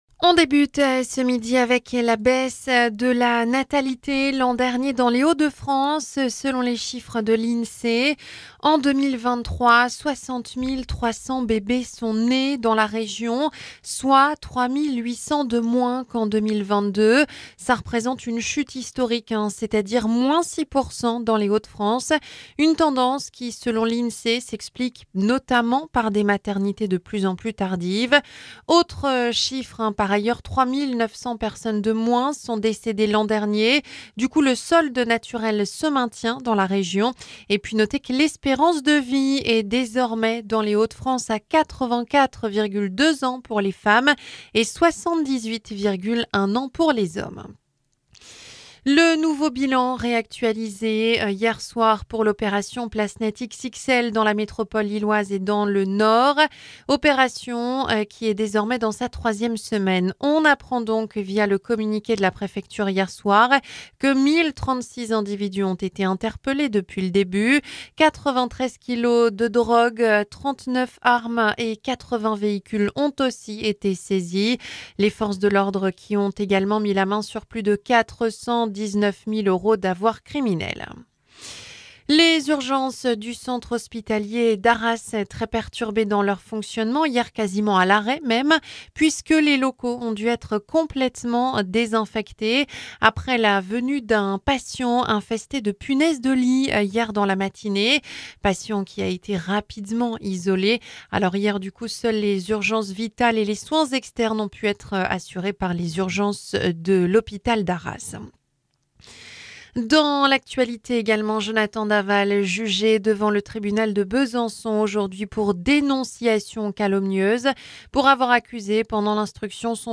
Journal 12h – La natalité en baisse l’an dernier dans les Hauts de France